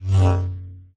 lightsaber2.ogg